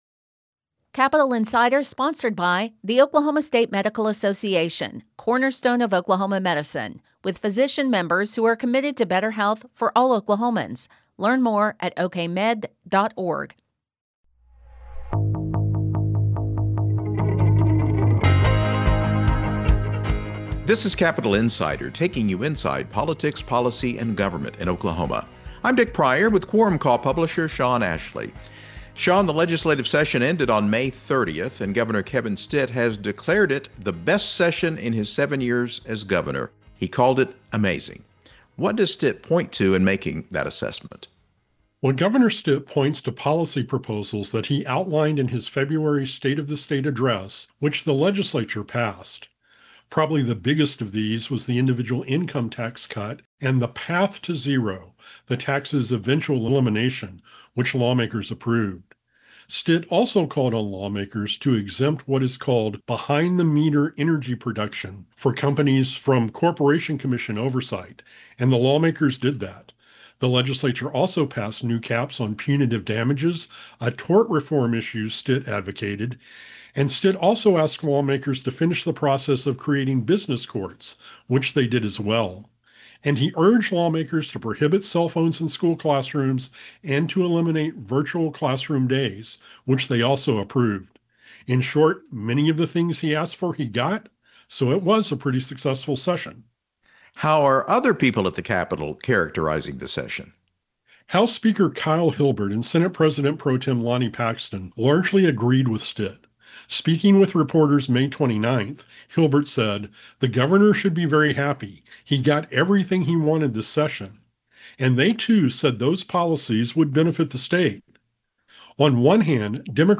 plus interviews with newsmakers.